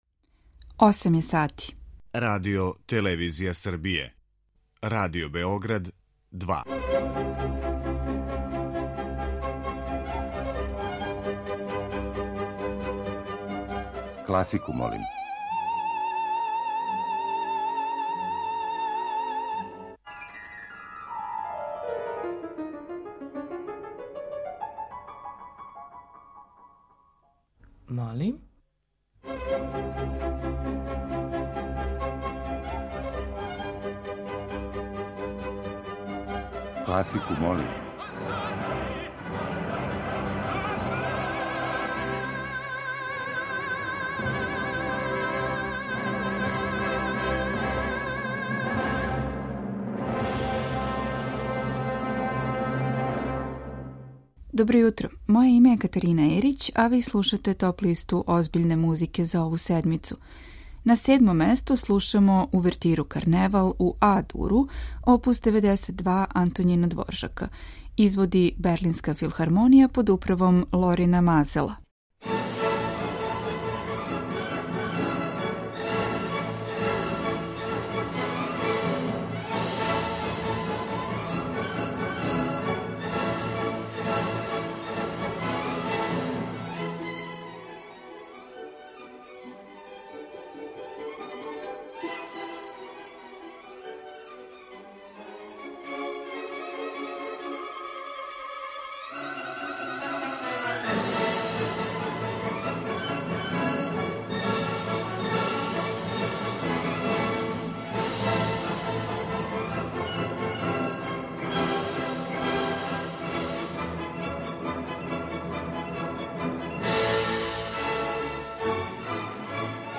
Топ-листа озбиљне музике, по избору слушалаца.